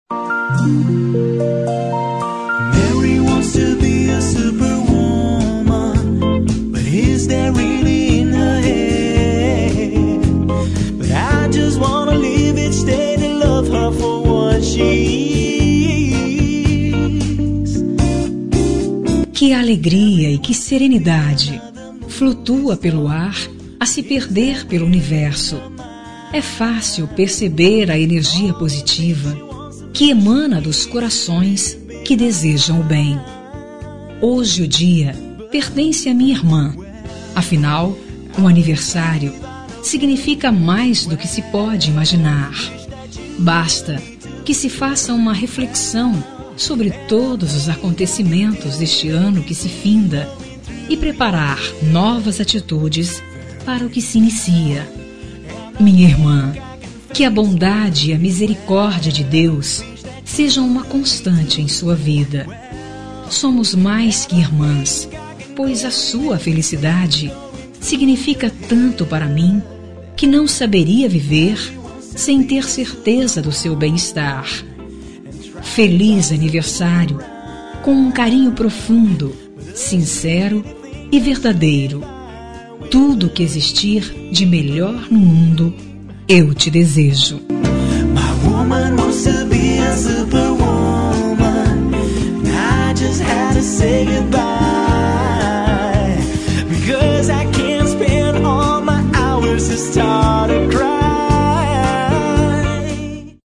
Aniversário de Irmã – Voz Feminina – Cód: 150105